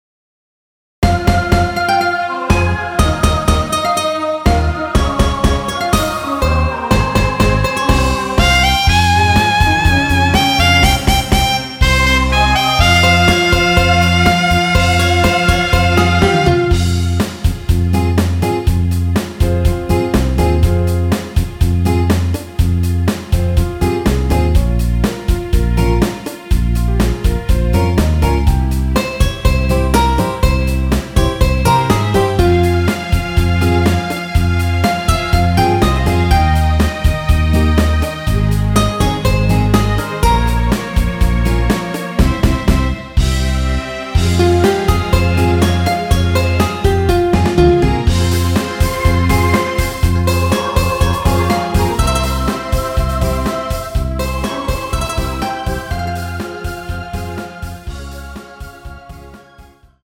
원키에서(+3)올린 MR입니다.
Fm
앞부분30초, 뒷부분30초씩 편집해서 올려 드리고 있습니다.
중간에 음이 끈어지고 다시 나오는 이유는